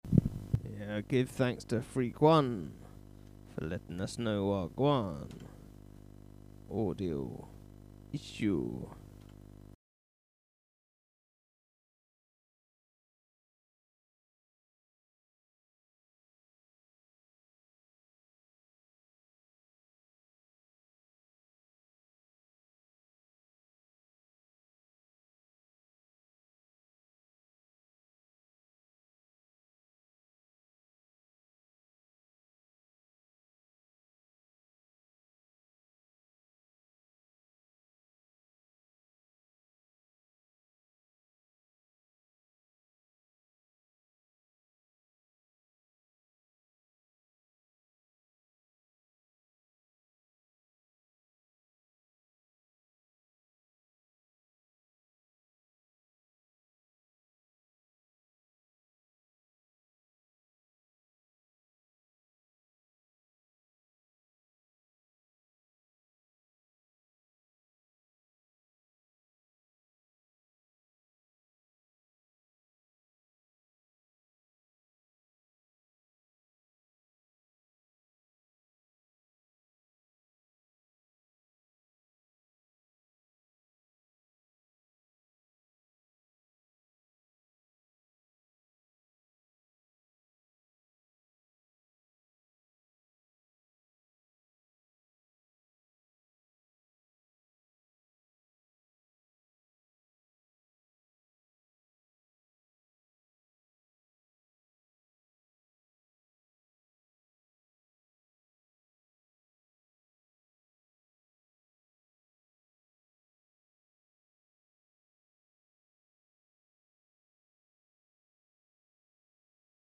brings twinkles along with a Bass Jam